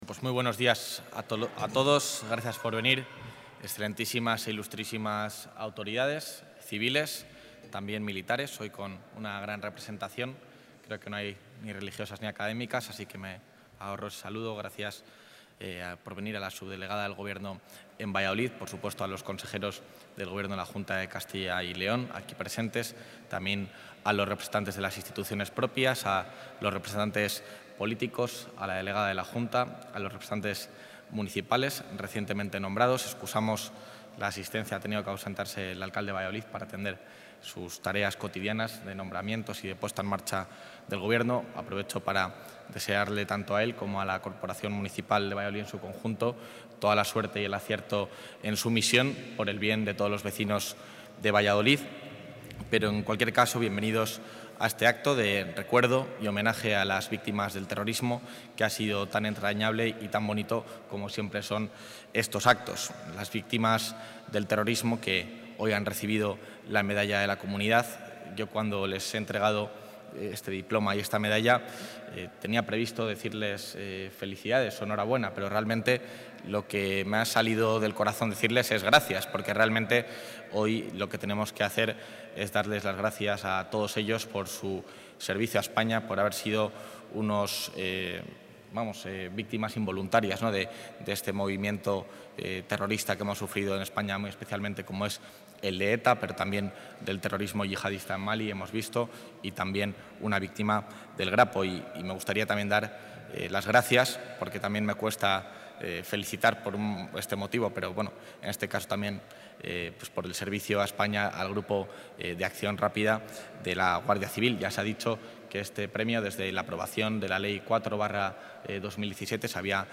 Intervención del vicepresidente de la Junta.
El vicepresidente de la Junta, Juan García-Gallardo, ha presidido hoy en el Monasterio de Nuestra Señora del Prado de Valladolid el acto con el que se ha conmemorado el día de recuerdo y homenaje a las víctimas del terrorismo en Castilla y León.